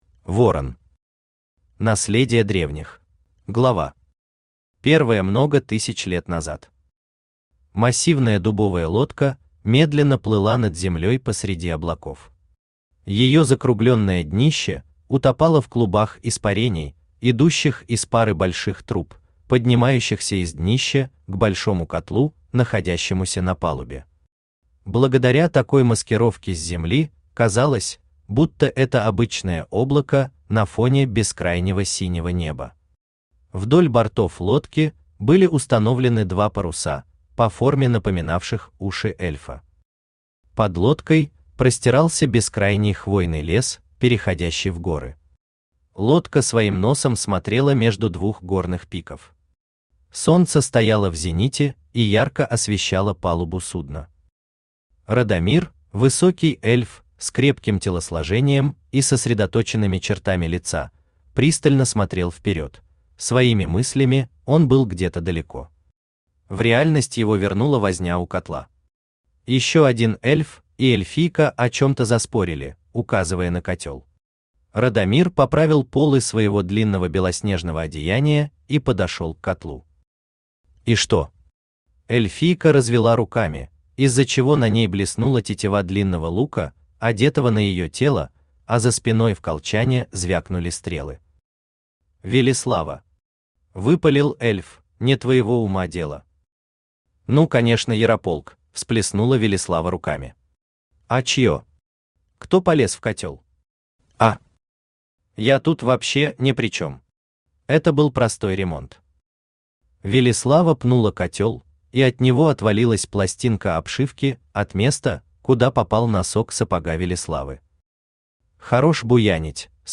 Аудиокнига Наследие древних | Библиотека аудиокниг
Aудиокнига Наследие древних Автор Ворон Читает аудиокнигу Авточтец ЛитРес.